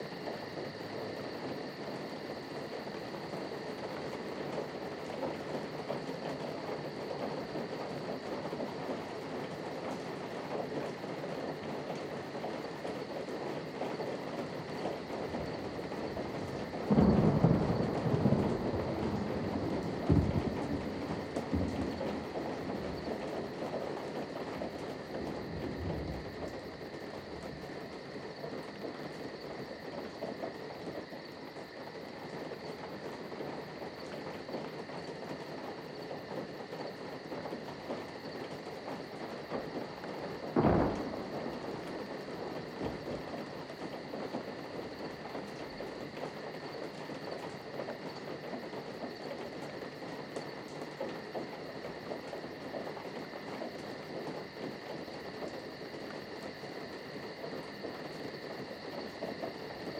Inside Night Storm.ogg